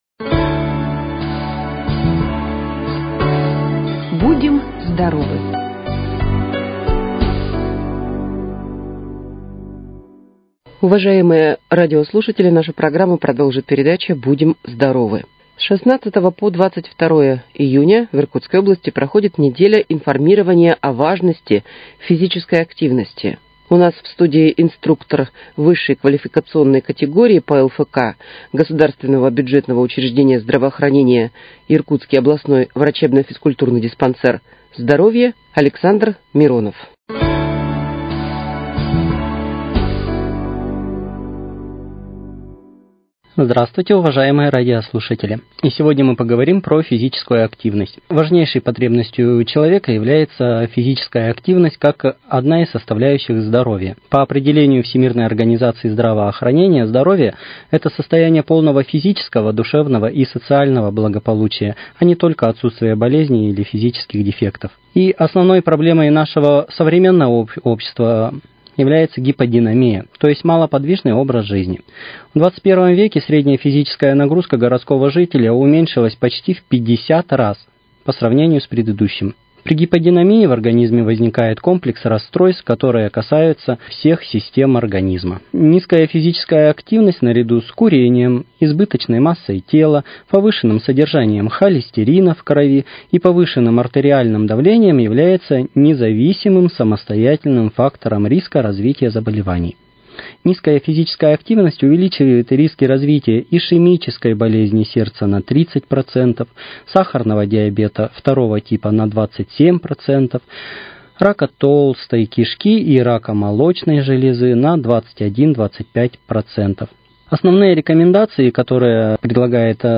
С 16 по 22 июня в Иркутской области проходит «Неделя информирования о важности физической активности». В студии Иркутского радио